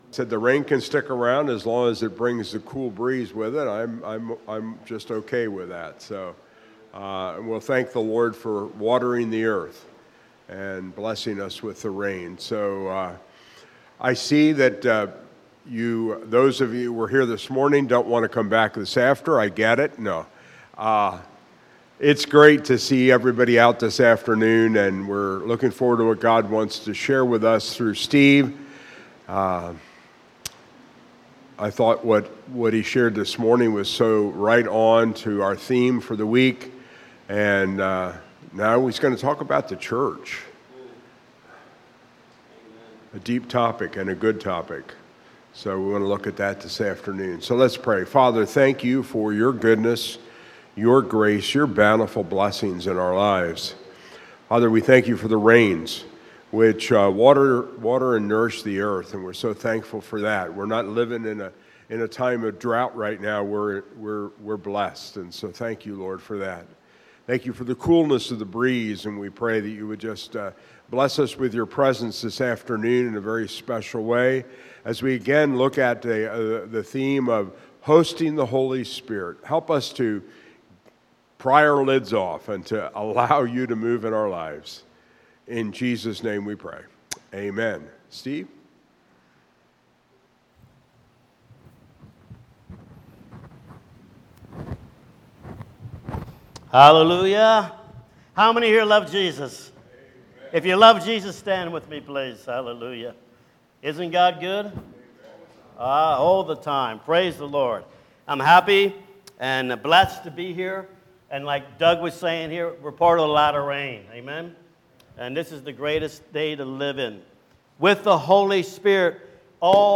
Series: Campmeeting 2025